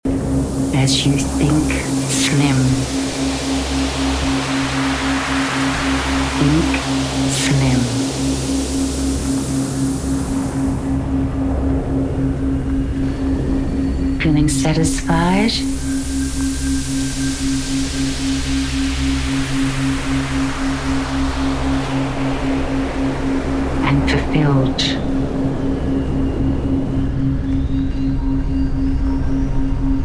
Binaural beats work by sending slightly different sound wave frequencies to each ear, guiding your brain into specific states through brainwave frequency entrainment.